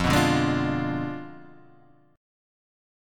F Diminished 7th